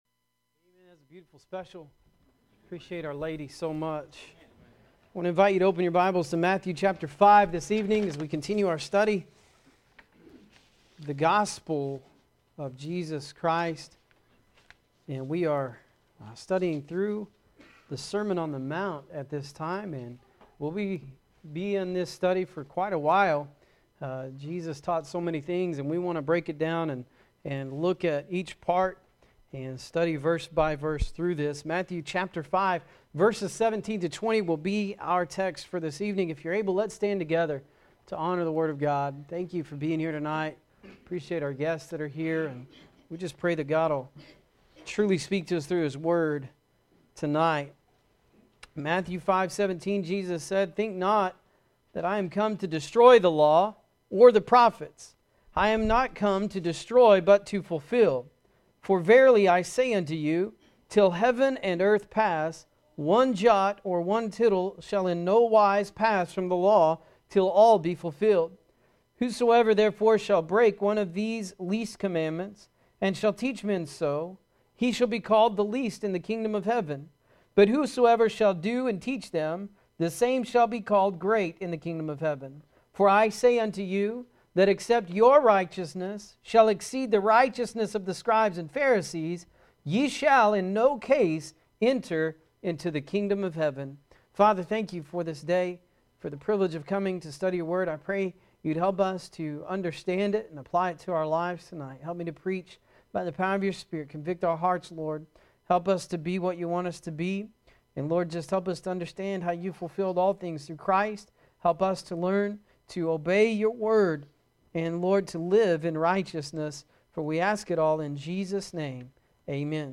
Sermon on the Mount Continued